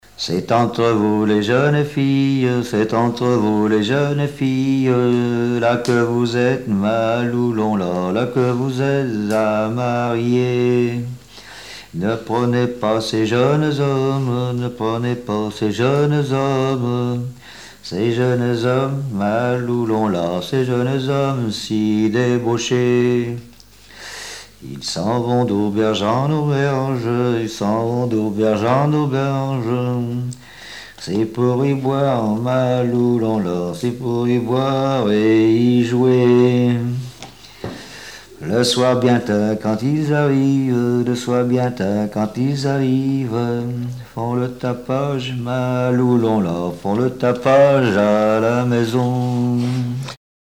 Genre laisse
Répertoire de chansons populaires et traditionnelles
Pièce musicale inédite